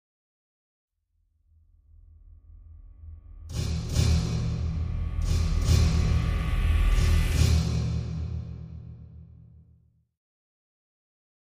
Drum Metallic Hit Reversed - Final Hits - B